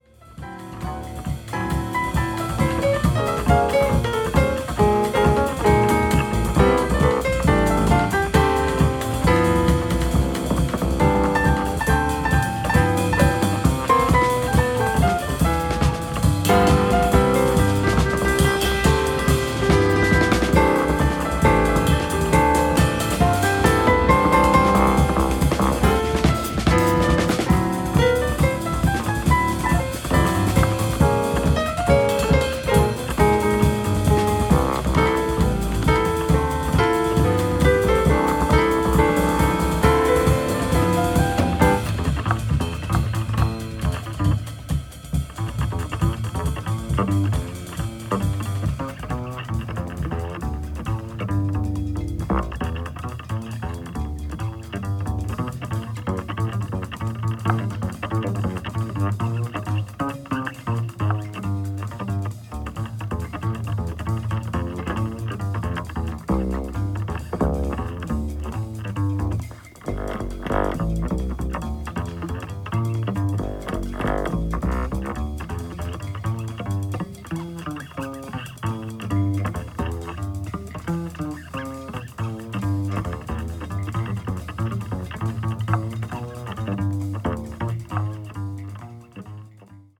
タイトでパワフルなドラムがダンサブルなジャズサンバ名曲